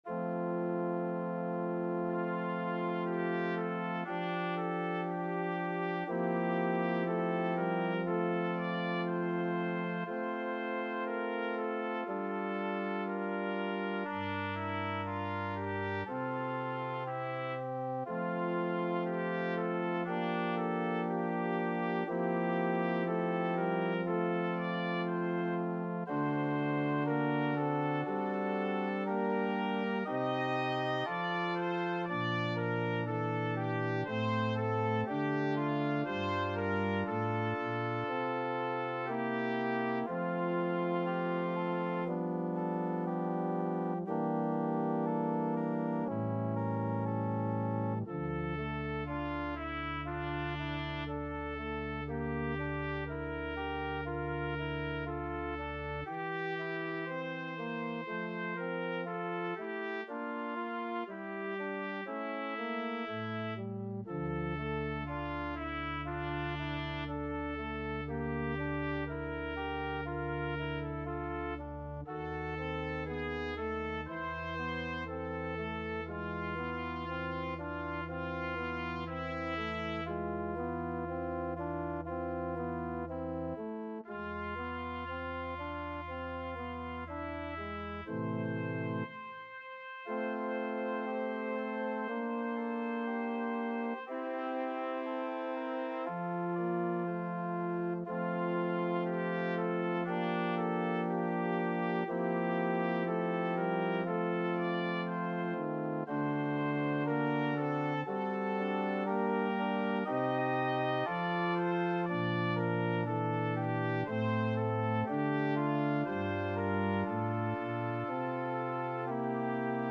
2/4 (View more 2/4 Music)
Classical (View more Classical Trumpet Music)